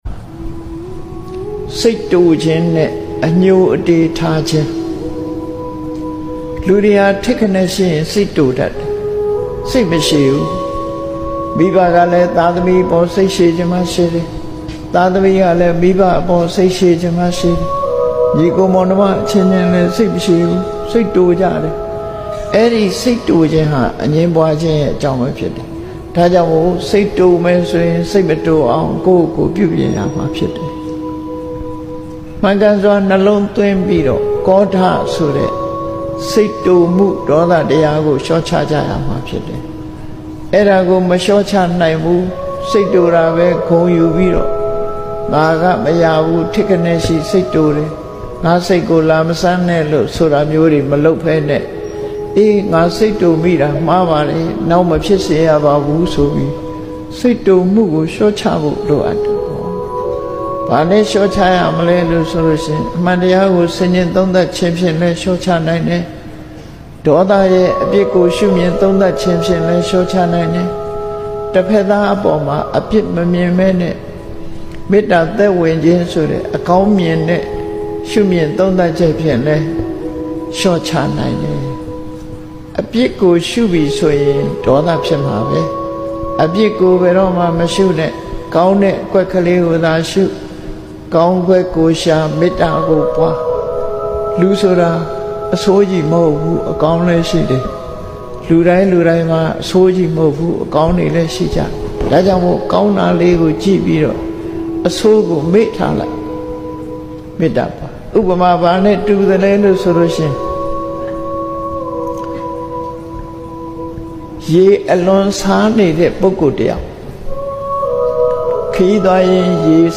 မှတ်သားဖွယ်ရာ တရားတော် များ 🙏